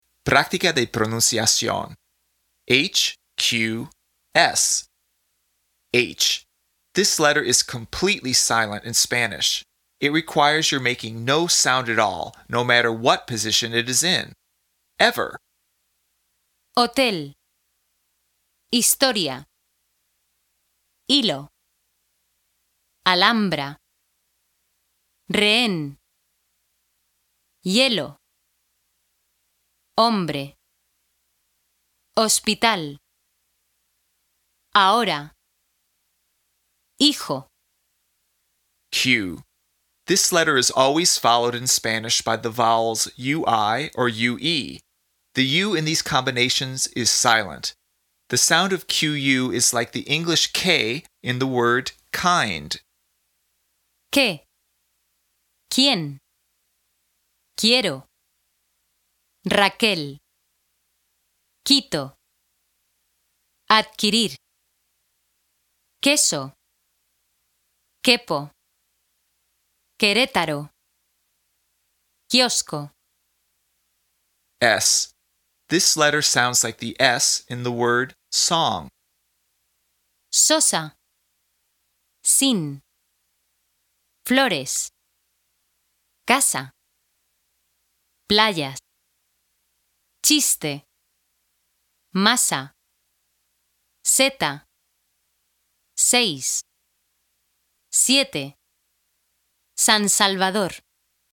PRÁCTICA DE PRONUNCIACIÓN
This letter is completely silent in Spanish.
The sound of “qu” is like the English “k” in the word “kind.”
This letter sounds like the “s” in the word “song.”